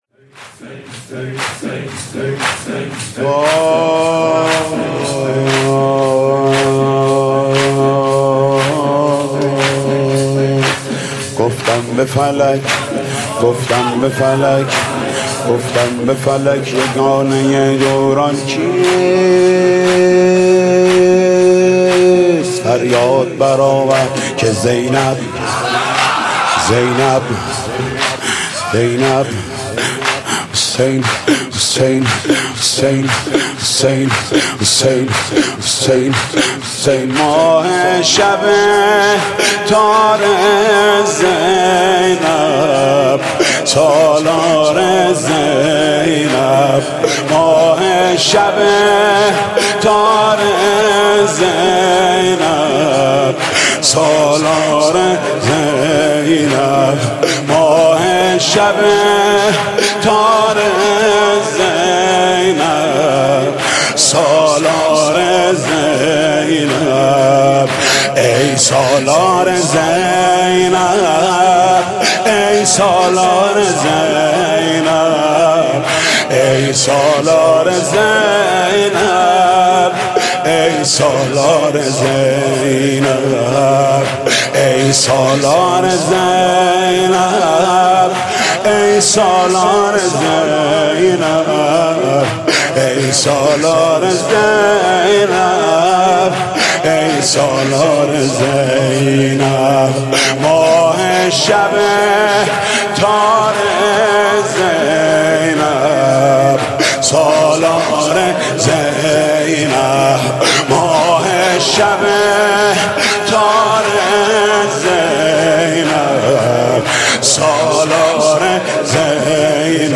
شب چهارم محرم94
مداحی